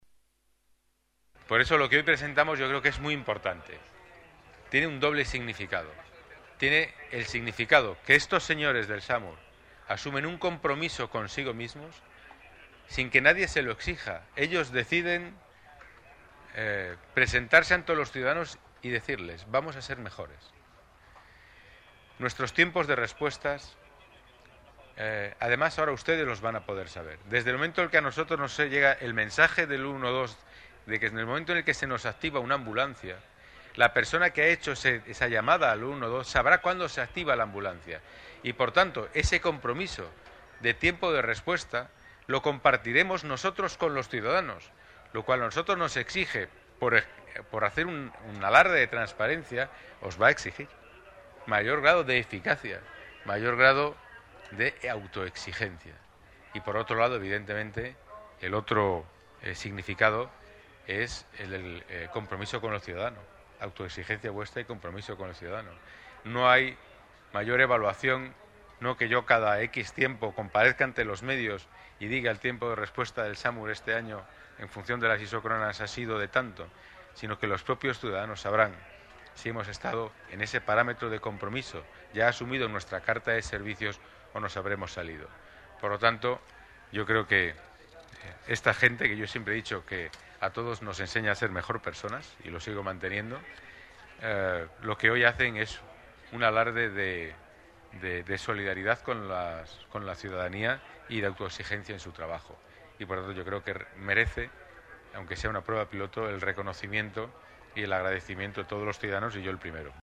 Nueva ventana:Declaraciones del delegado de Seguridad, Pedro Calvo: Nuevo dispositivo Samur